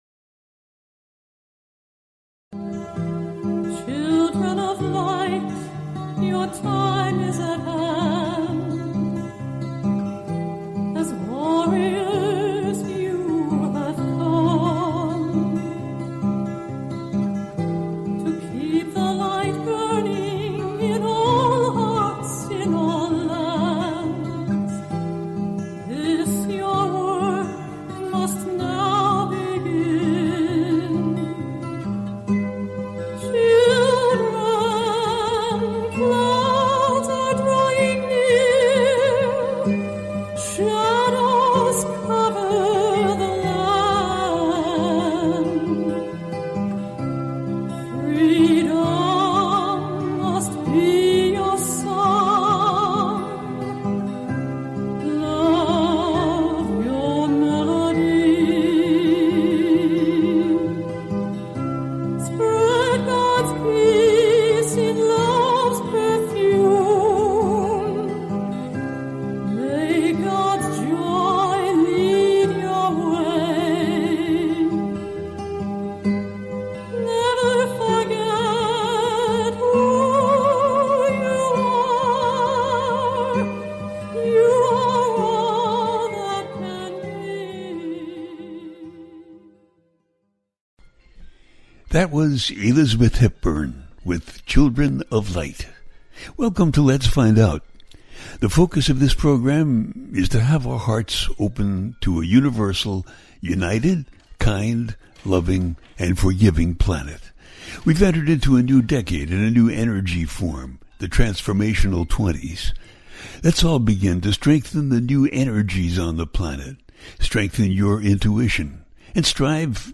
Talk Show
The listener can call in to ask a question on the air.
Each show ends with a guided meditation.